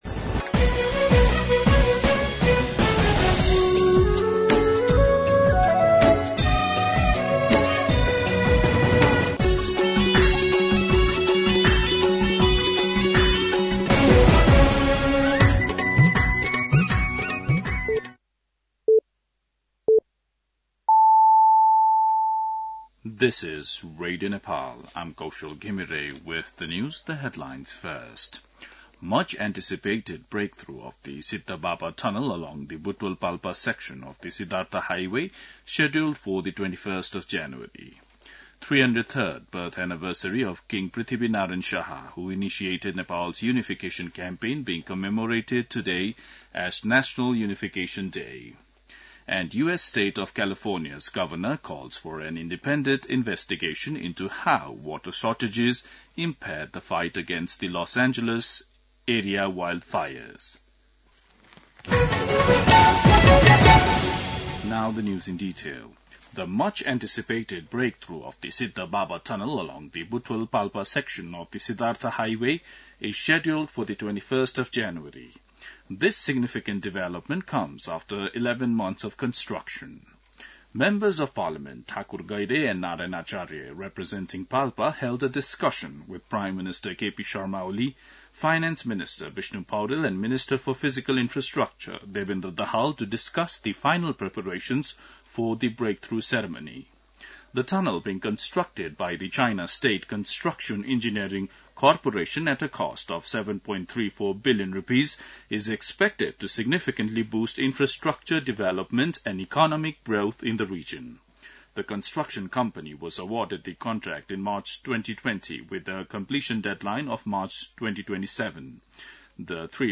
दिउँसो २ बजेको अङ्ग्रेजी समाचार : २८ पुष , २०८१
2pm-English-News-09-27.mp3